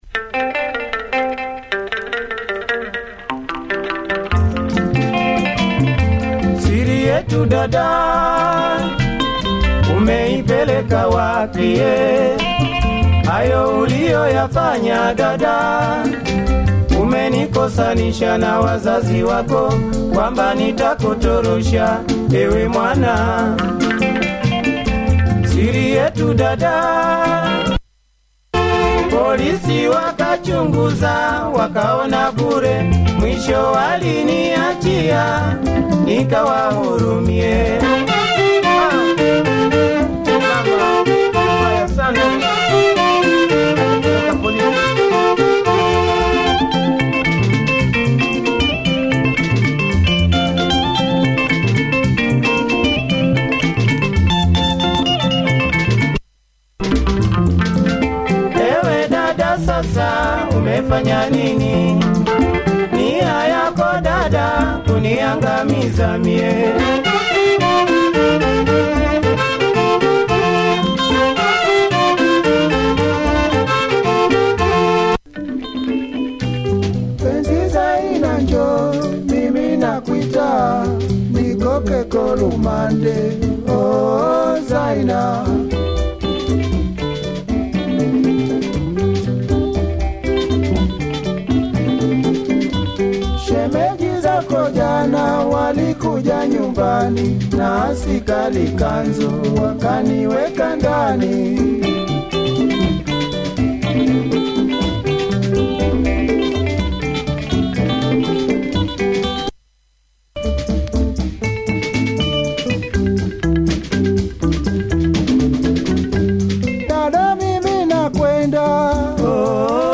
the gov. band of Tanzania
good rhythm guitar, drums, horns